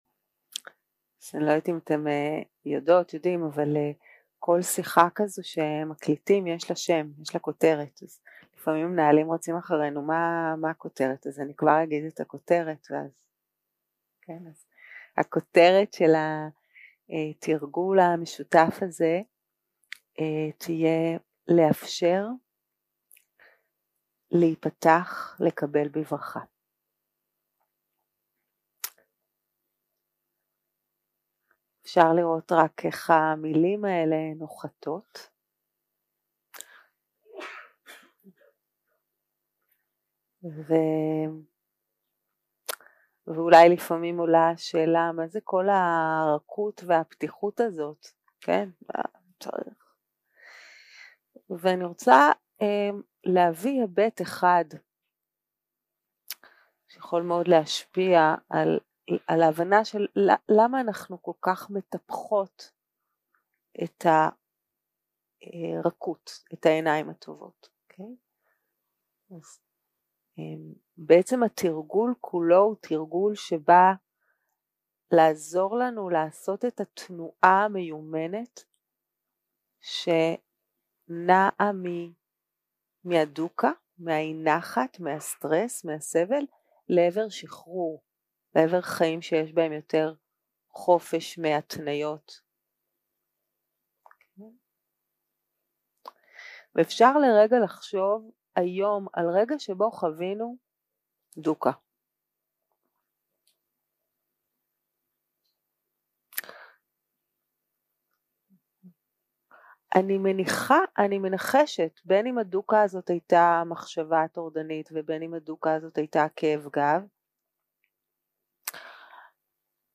יום 3 - הקלטה 6 - צהרים - מדיטציה מונחית - לאפשר, להיפתח, לקבל בברכה
סוג ההקלטה: מדיטציה מונחית שפת ההקלטה